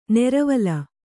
♪ neravala